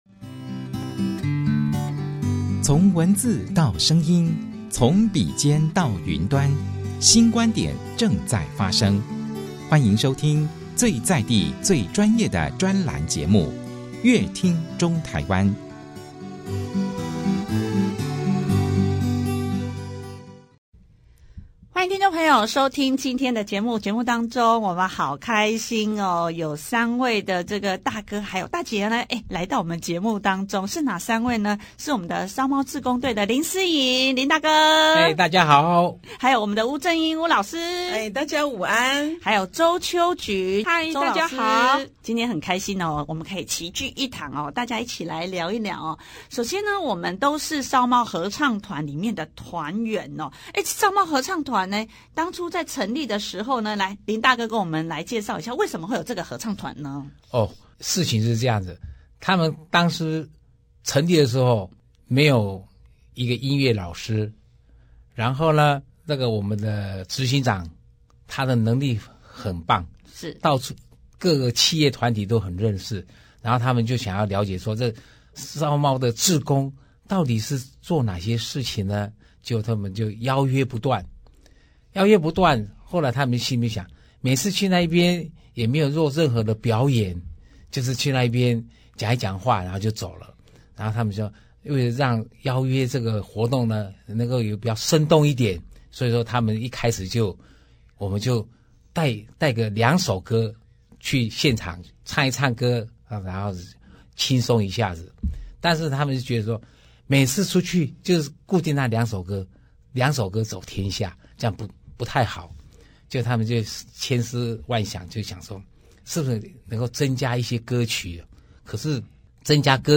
樂齡歡唱 精彩人生 唱歌的好處真的很多……三位合唱團團員在節目中津津樂道述說加入合唱團讓人生更充滿樂趣！